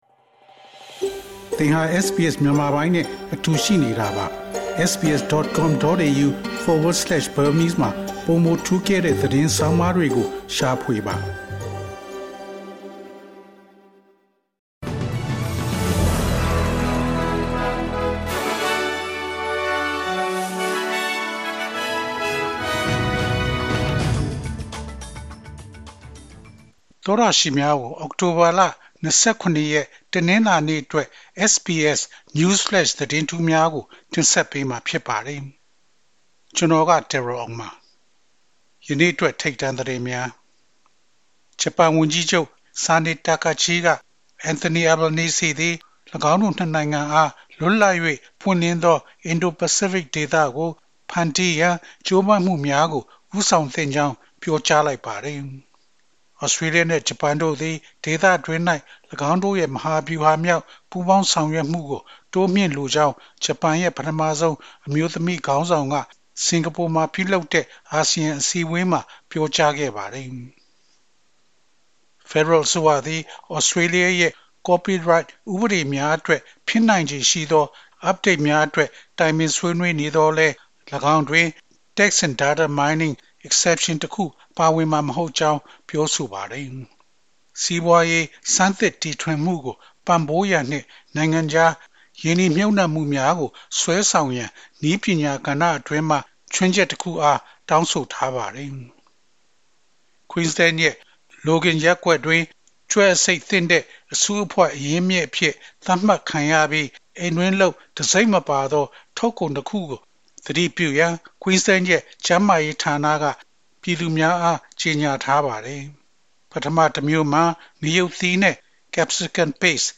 SBS မြန်မာ ၂၀၂၅ ခုနှစ် အောက်တိုဘာလ ၂၇ ရက် နေ့အတွက် News Flash သတင်းများ။